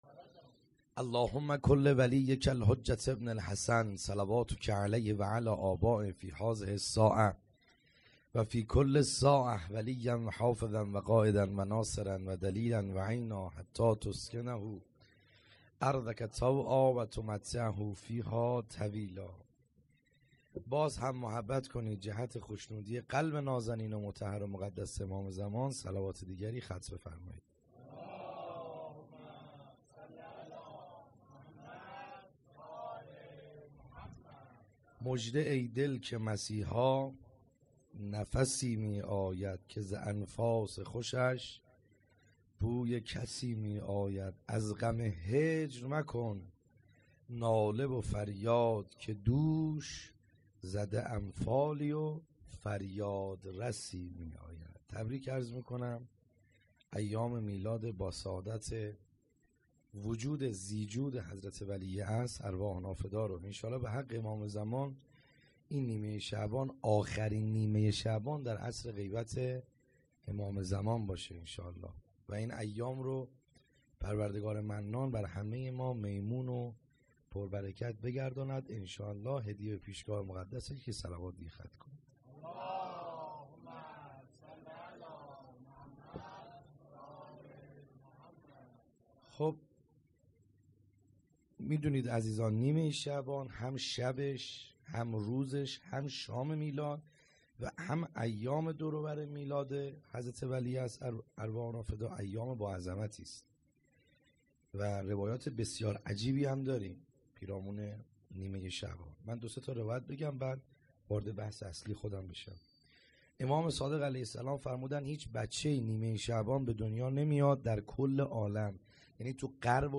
خیمه گاه - بیرق معظم محبین حضرت صاحب الزمان(عج) - سخنرانی | کیفیت عالی